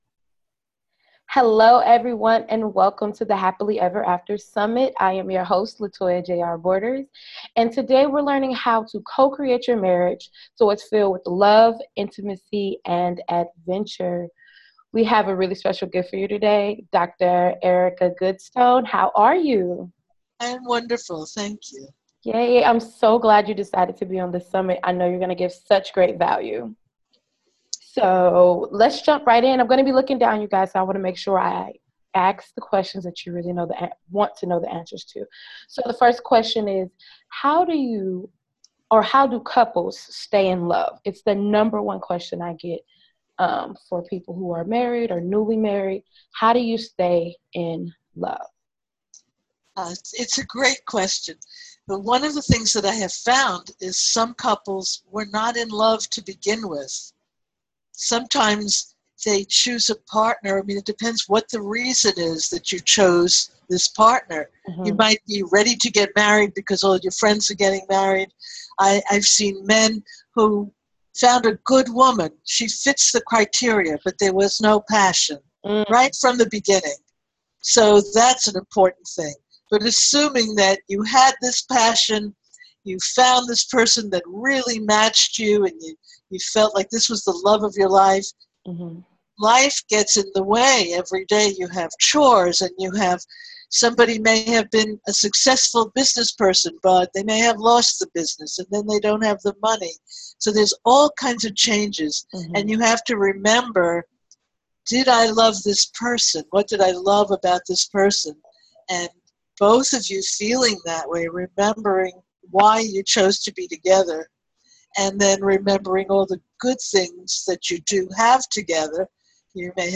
HAPPILY EVER AFTER SUMMIT AUDIO INTERVIEW